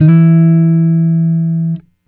Guitar Slid Octave 05-E2.wav